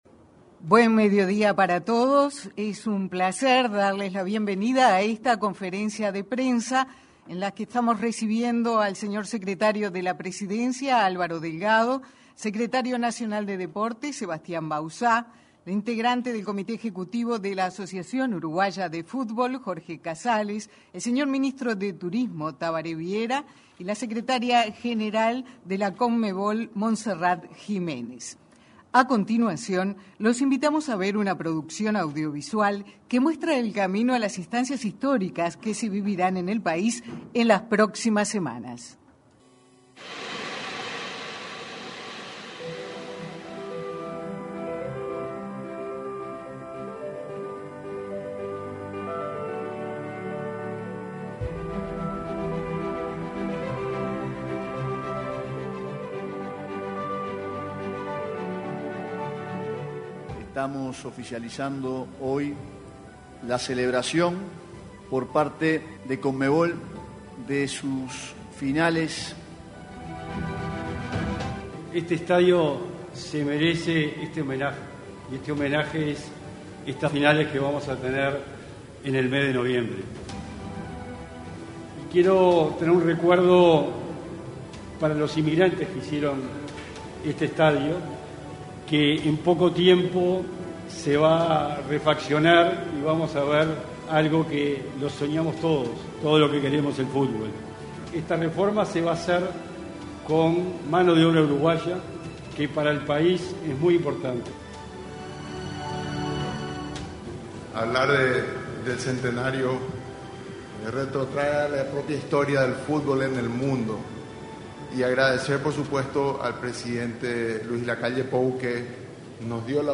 Conferencia de prensa sobre próximos eventos deportivos en Uruguay
En la conferencia realizada este martes 16, se expresaron el secretario de la Presidencia, Álvaro Delgado; el secretario nacional del Deporte,